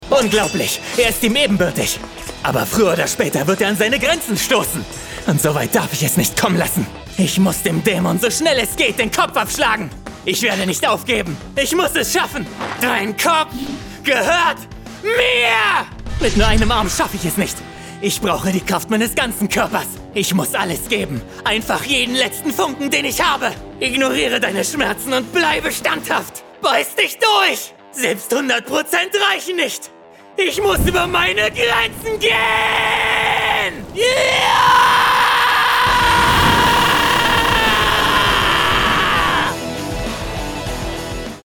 Sprecher, Synchronsprecher, Sänger